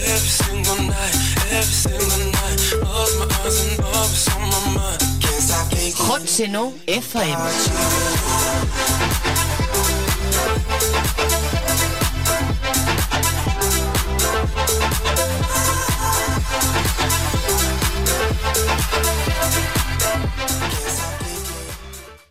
Tema musical i identificació